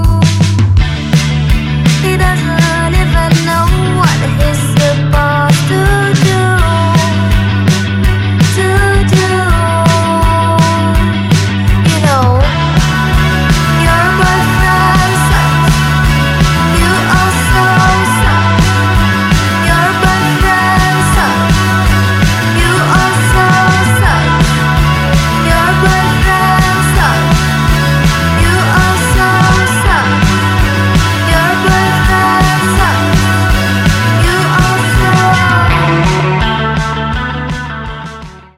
aransemen musik dream pop
gitar